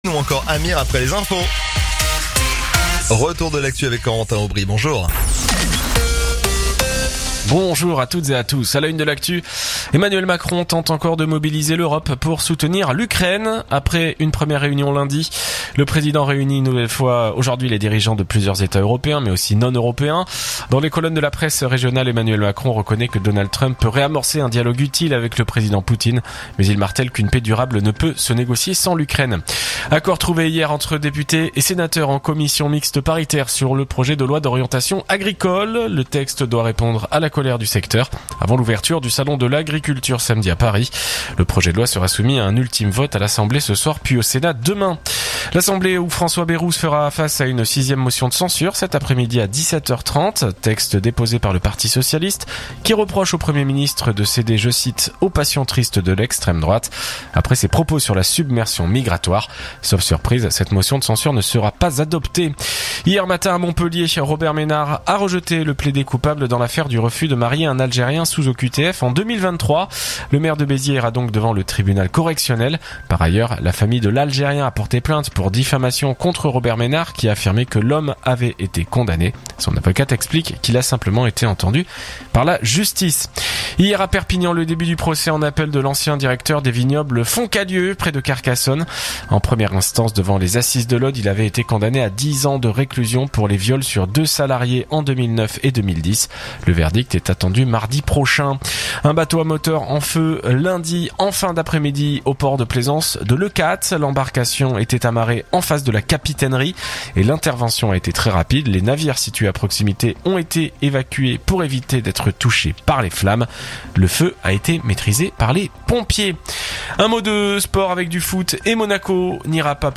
info_narbonne_toulouse_299.mp3